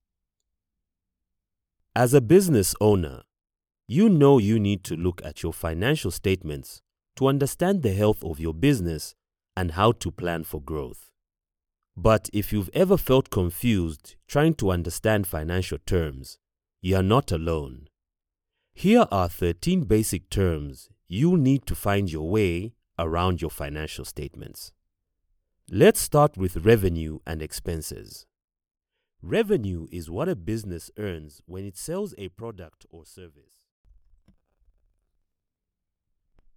Inglés (África)
E-learning
Sennheiser MKH 416
ProfundoBajo